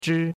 zhi1.mp3